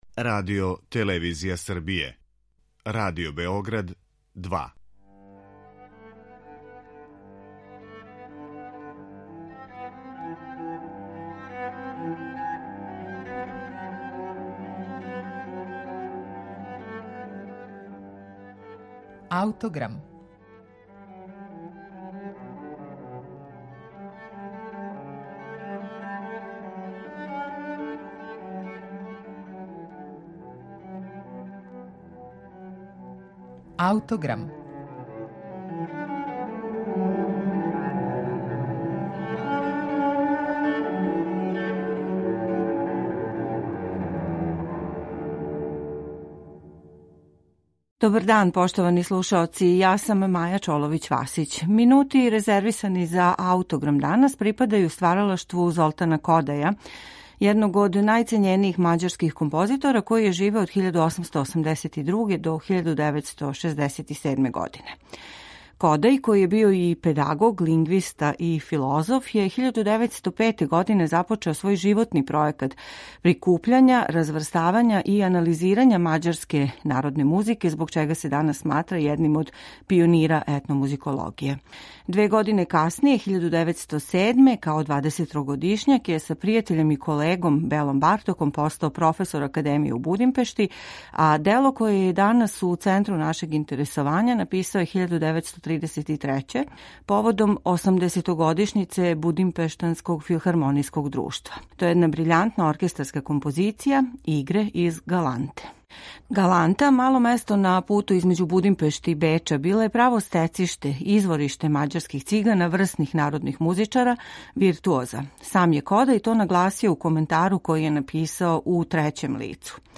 У њој је обрадио пет игара које је чуо у малом месту Галанта, стецишту мађарских Цигана, врсних народних музичара и виртуоза. У петнаестоминутном оркестарском делу игре се нижу једна за другом без прекида и уз непрестано убрзавање темпа, све до ефектног финала. Фестивалским оркестром из Будимпеште диригује Иван Фишер.